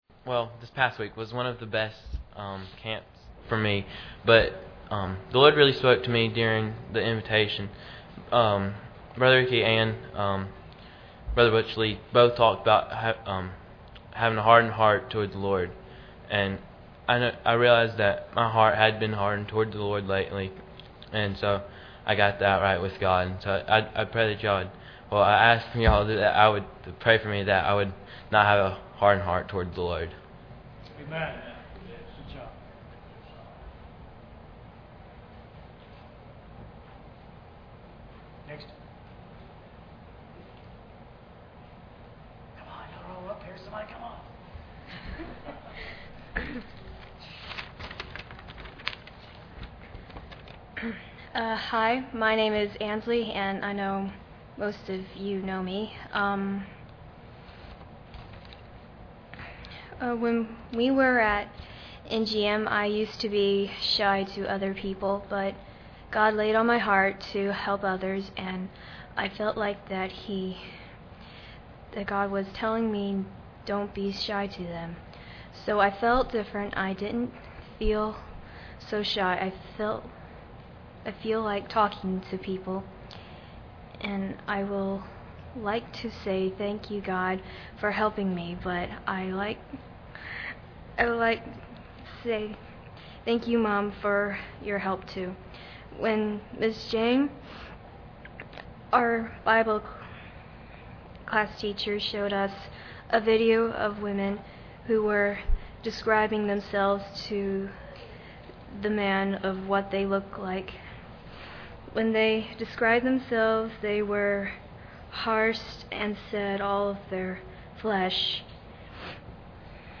Bible Text: Proverbs 19:23-24 | Preacher: CCBC Members | Series: General
Some of the testimonies failed to record due to a technical issue.